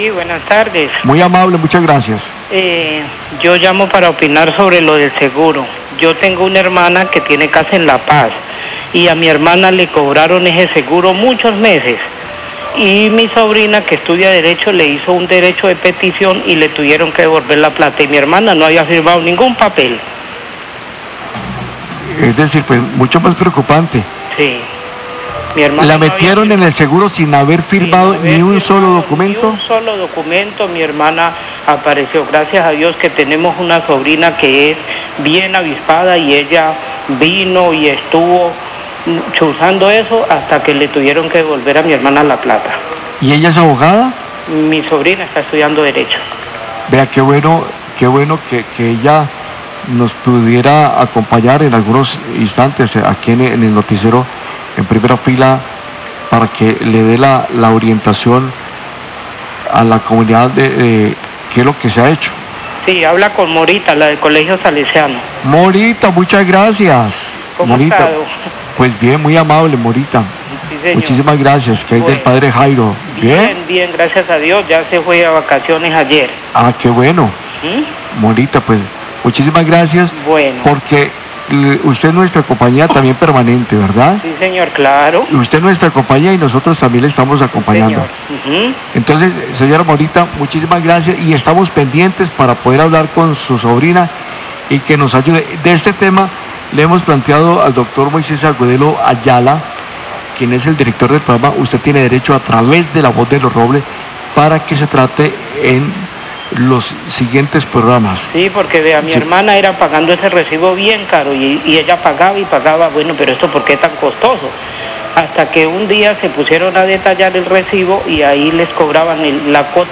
OYENTE COMENTA CASO DONDE LOGRÓ QUE LE DEVOLVIERAN EL PAGO DE SEGURO NO AUTORIZADO, ROBLES 12 18PM
Radio
Oyente comenta cómo logro que le devolvieran el dinero que durante meses pagó por el seguro de Sura.